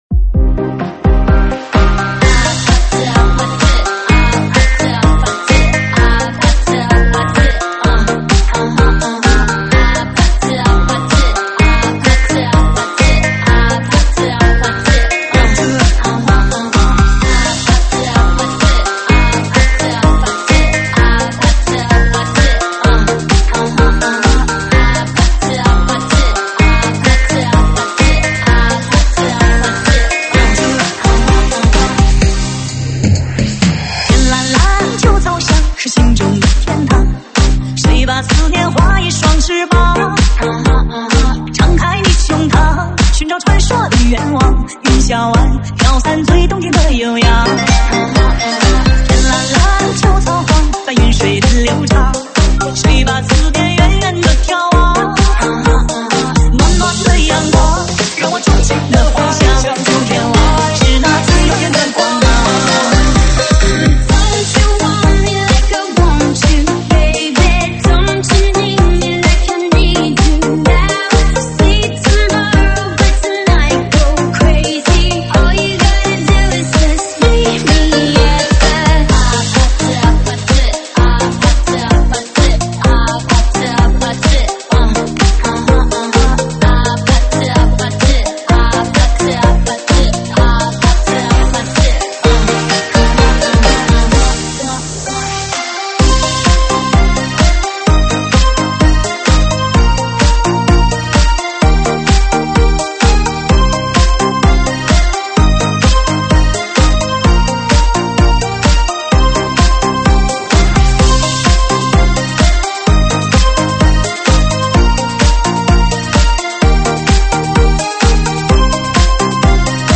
车载大碟